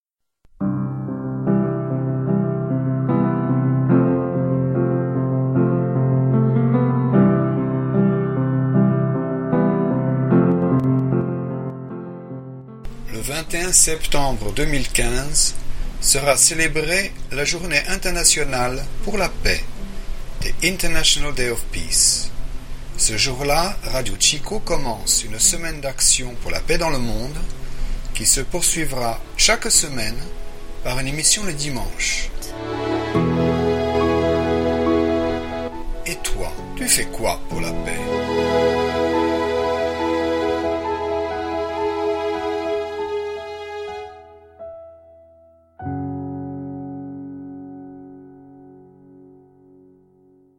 Jingle Weltfriedenswoche Franzoesisch
Weltfrieden franzoesich Jingle.mp3